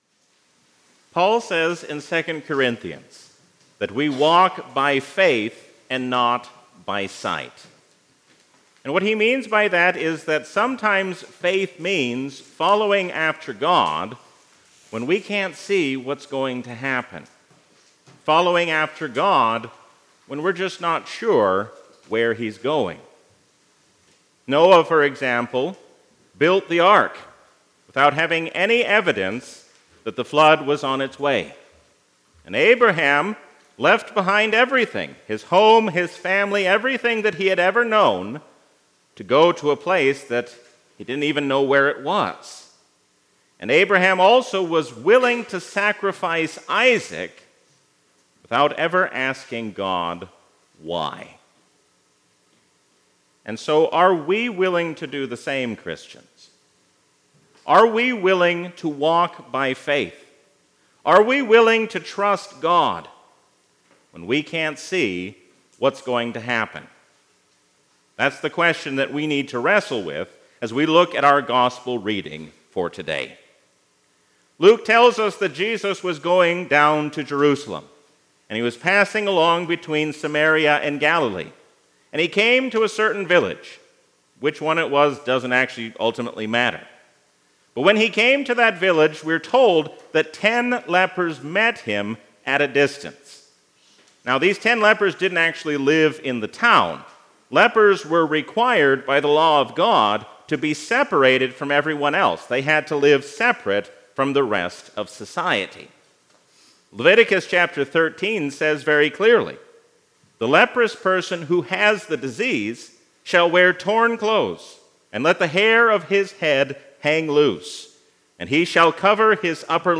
A sermon from the season "Trinity 2023." When God is our first priority, then there is no need to worry about the things of this life.